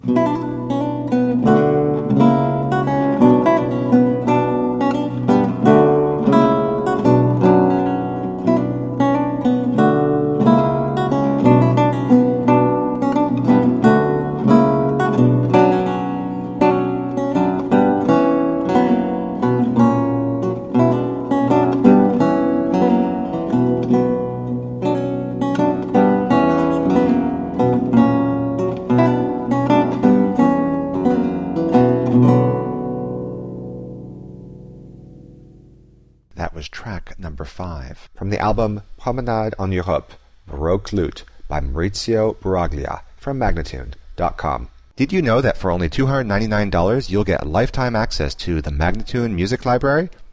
A marvelous classical spiral of lute sounds.
Classical, Baroque, Instrumental
Lute